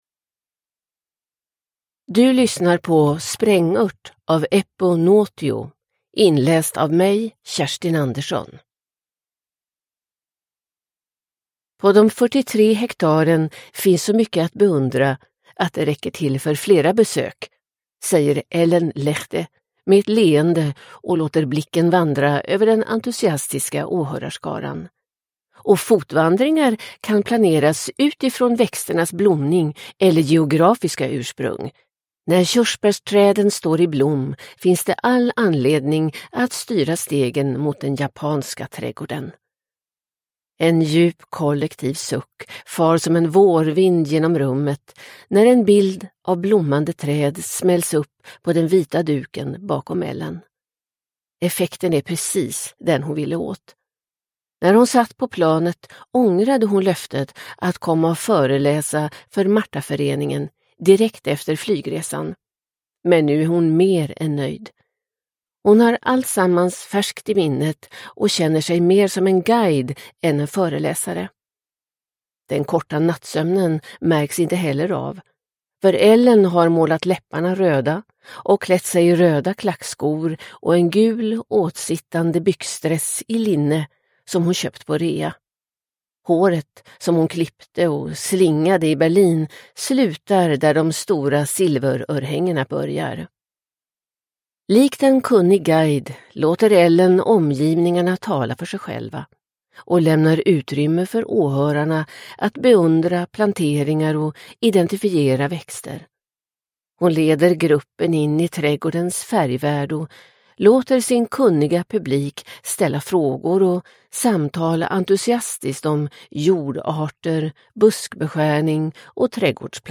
Sprängört – Ljudbok – Laddas ner